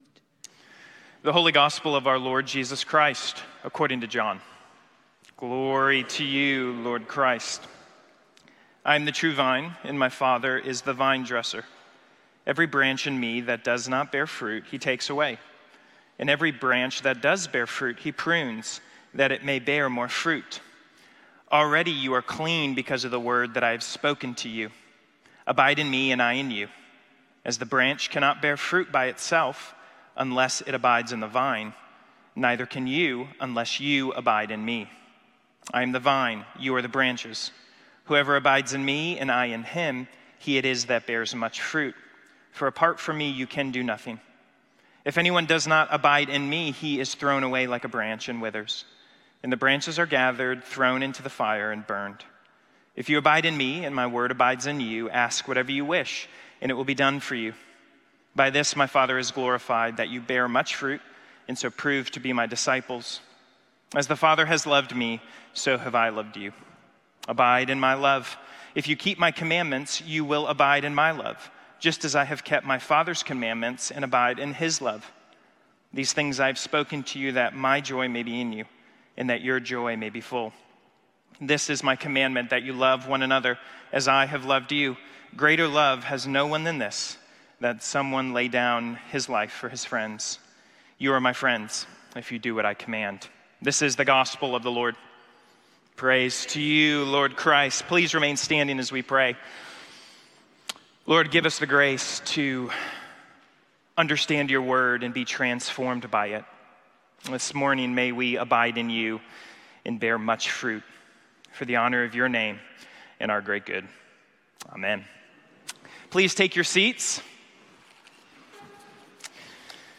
Sermons - Holy Trinity Anglican Church